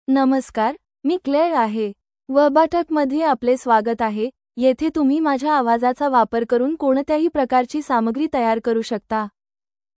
Claire — Female Marathi AI voice
Claire is a female AI voice for Marathi (India).
Voice sample
Listen to Claire's female Marathi voice.
Female
Claire delivers clear pronunciation with authentic India Marathi intonation, making your content sound professionally produced.